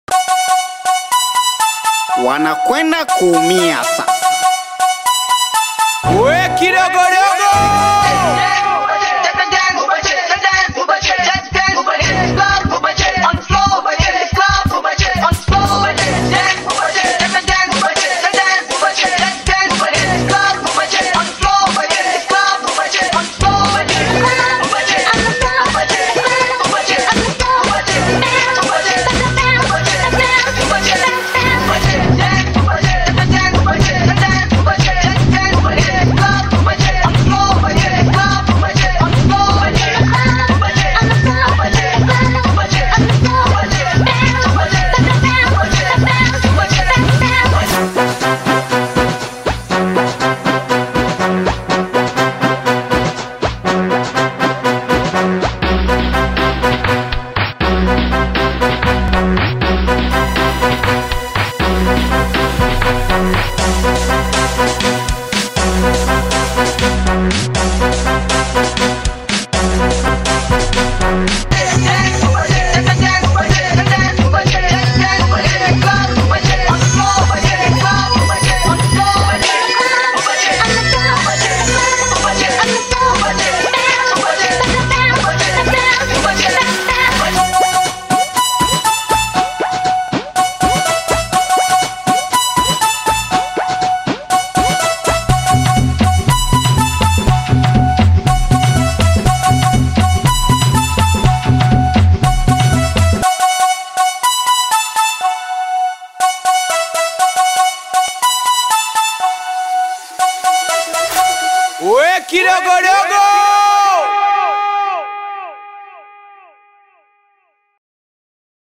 catchy rhythm